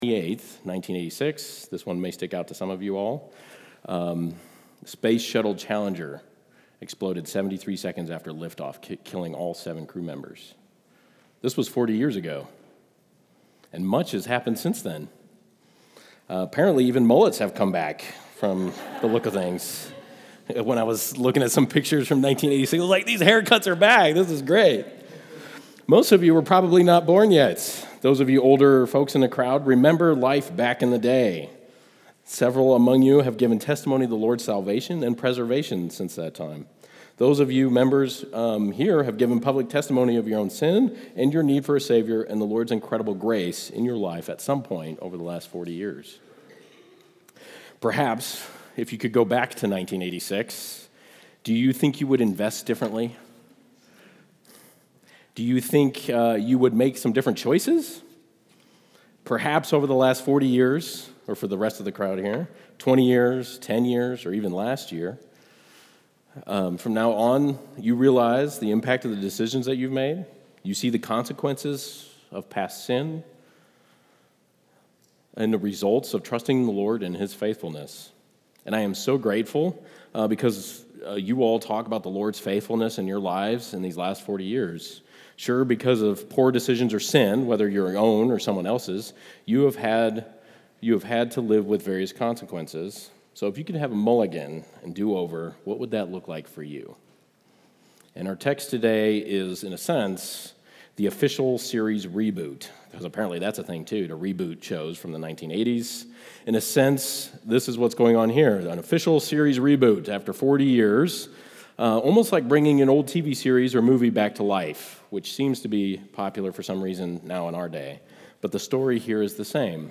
Teaching Apple Podcast Sunday’s Sermon Loading Content...